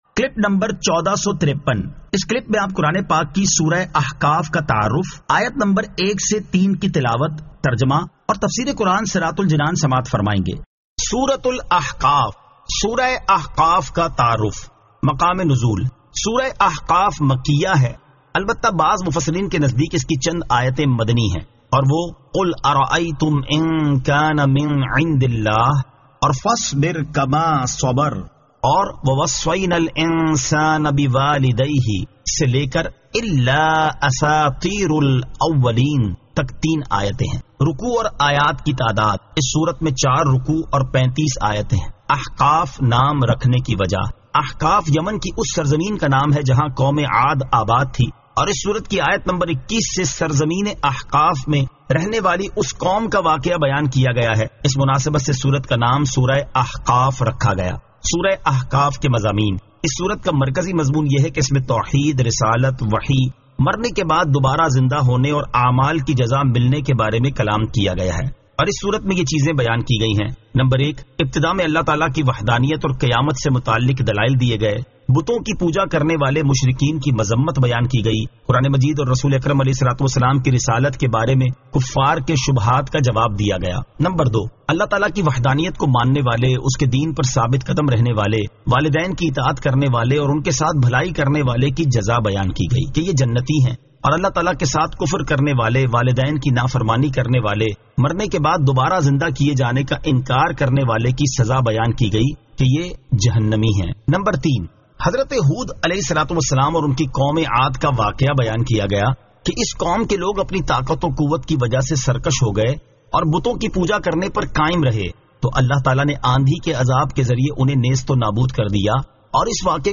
Surah Al-Ahqaf 01 To 03 Tilawat , Tarjama , Tafseer